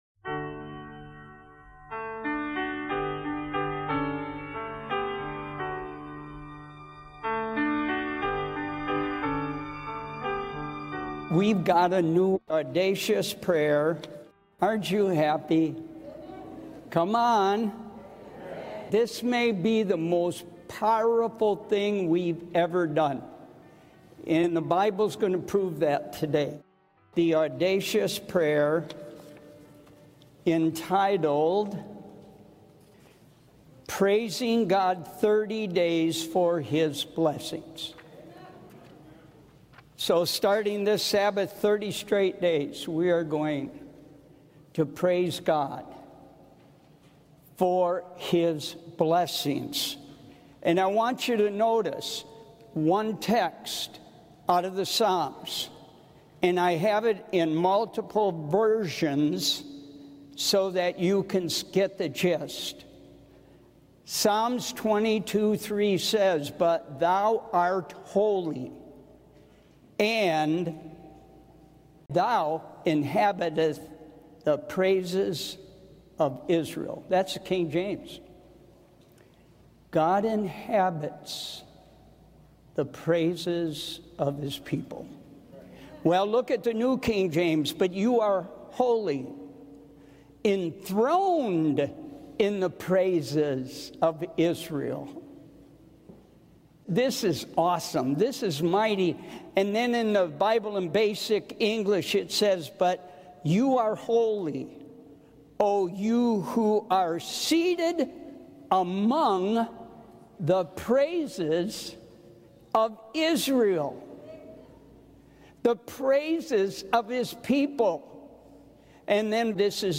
Experience the power of praise like never before in this inspiring sermon, The Power of Praise. Discover how dedicating 30 days to praising God—without asking for anything—can transform your faith, bring breakthroughs, and invite God's presence into your life.